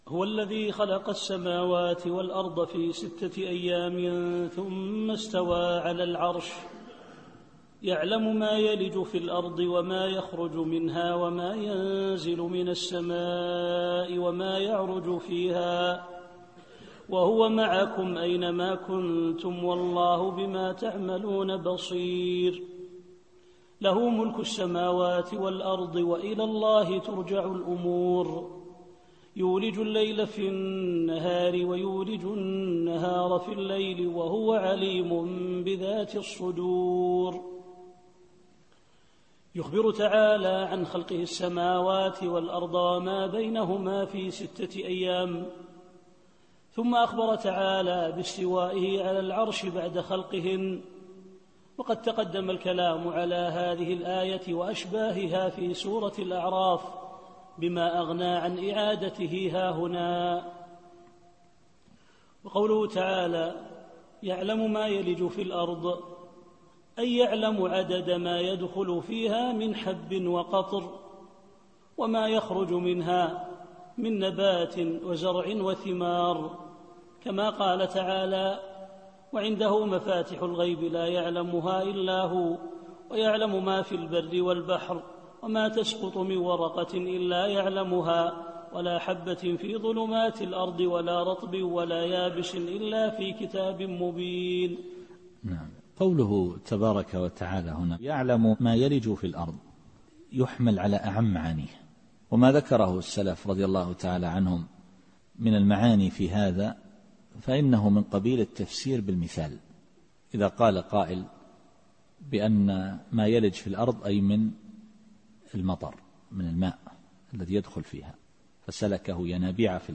التفسير الصوتي [الحديد / 4]